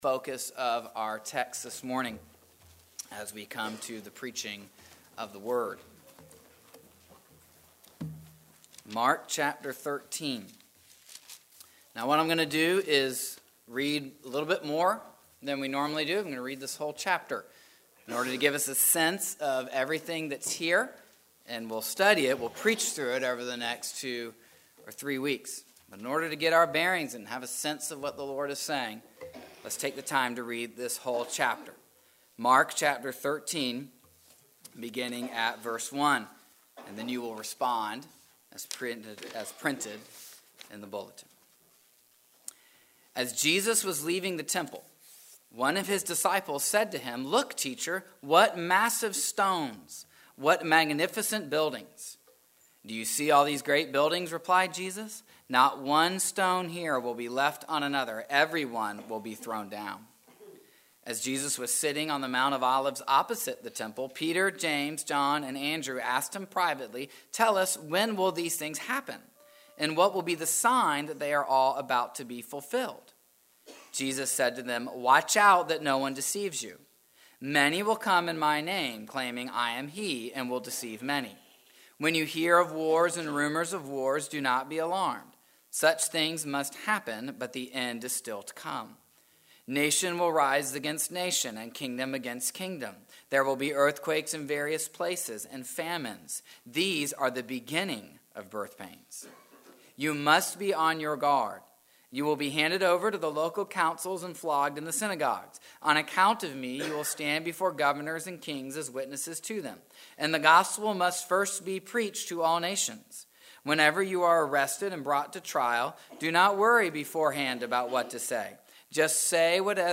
Mark 13:1-37 Service Type: Sunday Morning What is this passage about